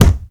punch_low_deep_impact_07.wav